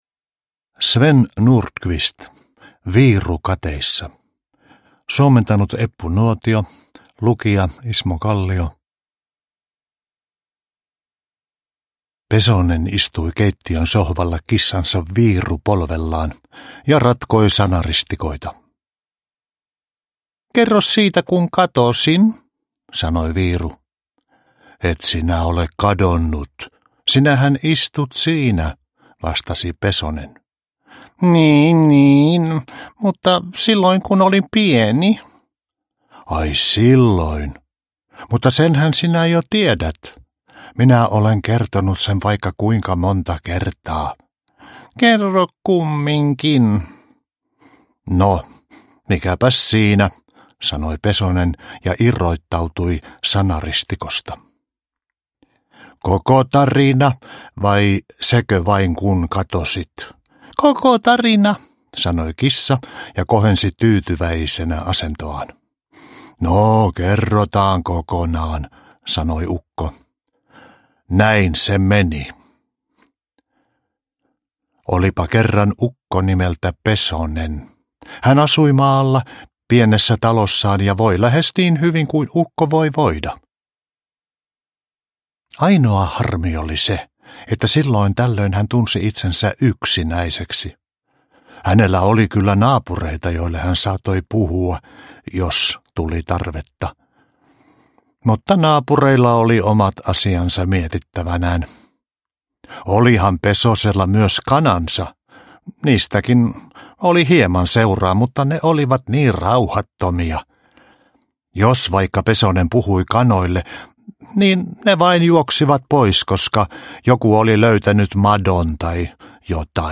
Viiru kateissa – Ljudbok – Laddas ner
Uppläsare: Ismo Kallio